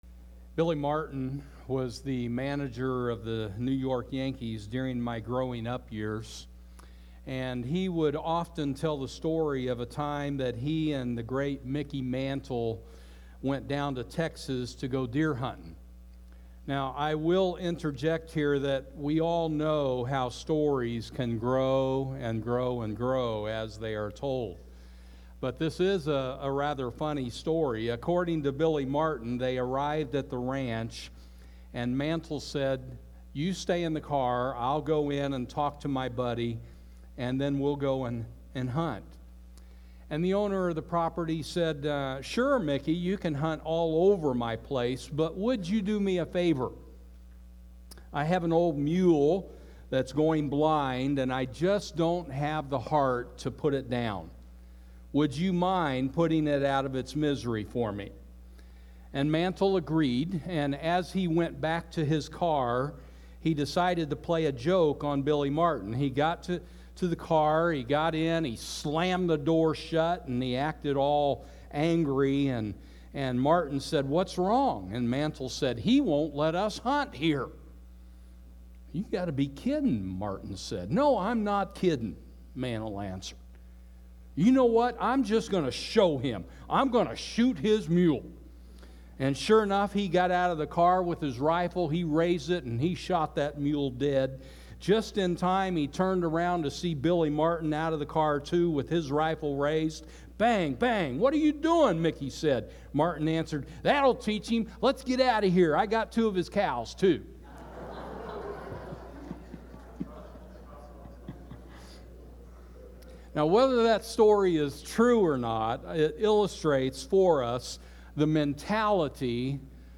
Sermons - Rinehart Church